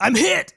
Im Hit.wav